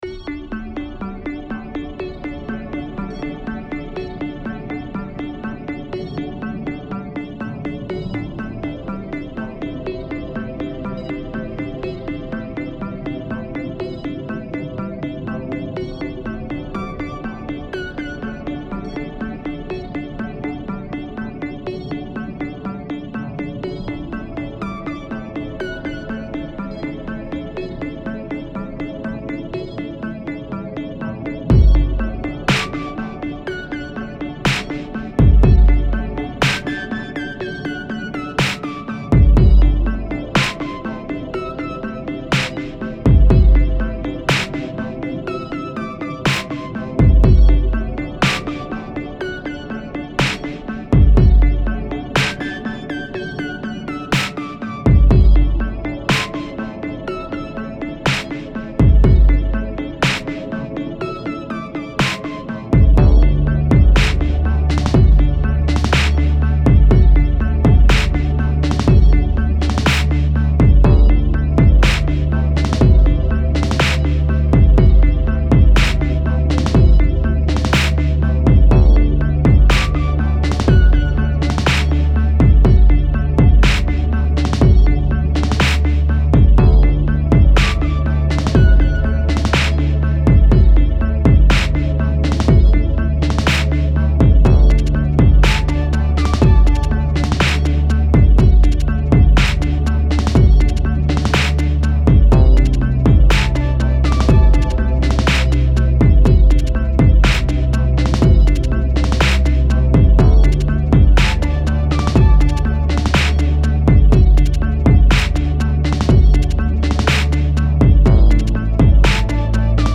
Style Style EDM/Electronic, Oldies
Mood Mood Bright, Mysterious, Relaxed
Featured Featured Drums, Synth
BPM BPM 122